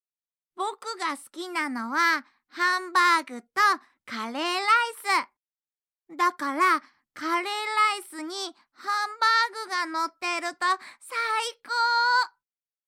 ボイスサンプル7（男の子）[↓DOWNLOAD]
声質は少しハスキーなところがあります。